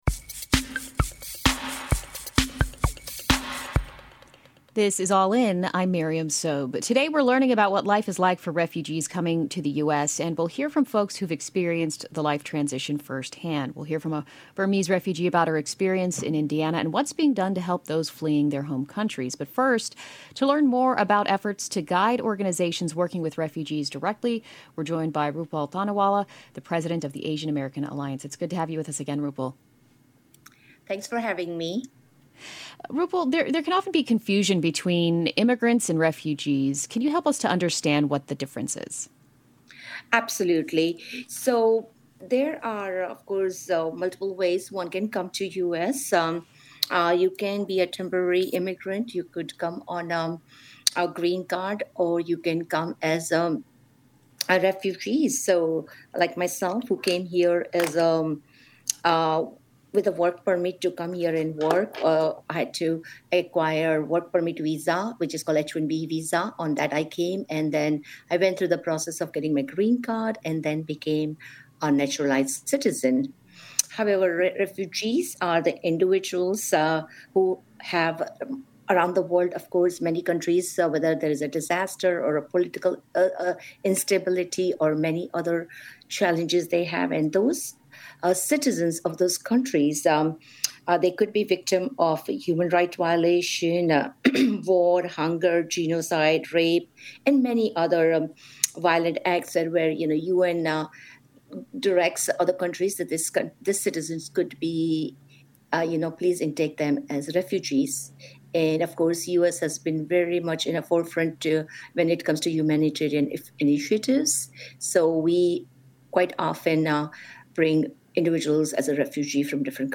Listen to the broadcast version of the show